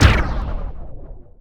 poly_explosion_antimatter.wav